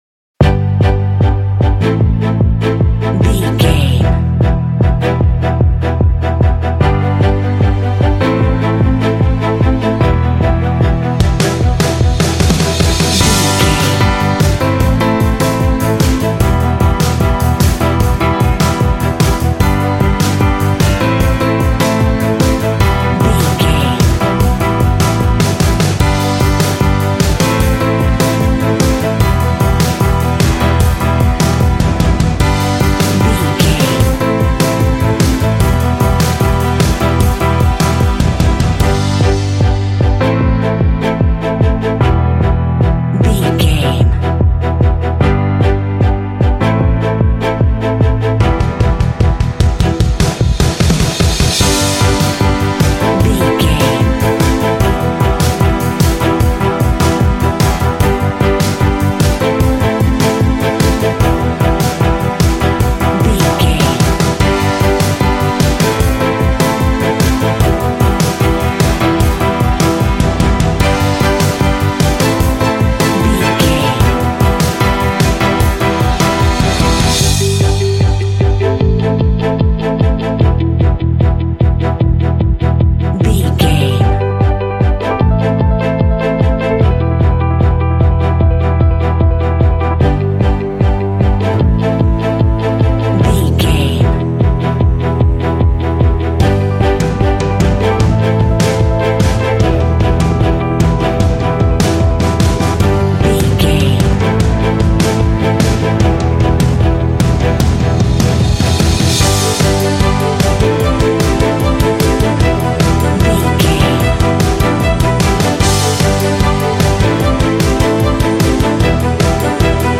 Epic / Action
Uplifting
Aeolian/Minor
proud
confident
bright
hopeful
elegant
romantic
strings
bass guitar
electric guitar
piano
drums
indie
alternative rock